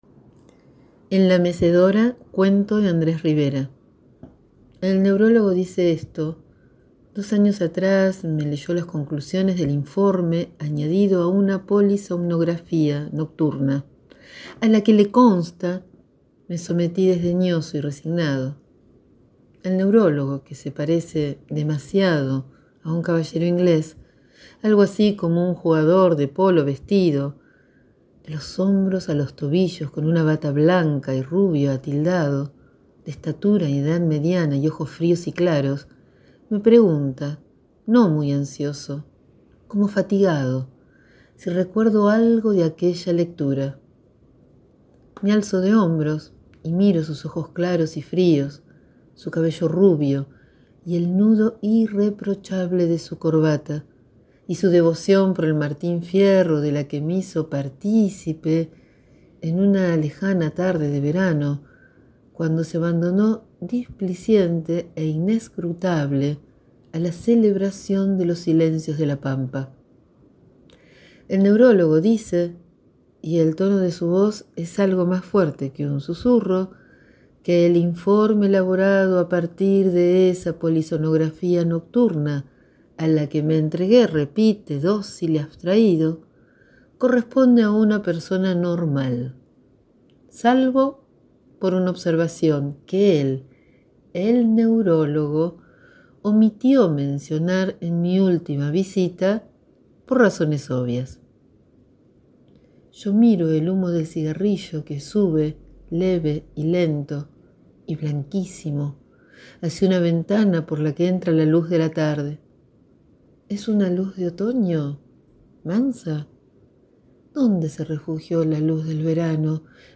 Hoy leo el cuento «La mecedora» de Andrés Rivera (Bs As1928-Córdoba 2016).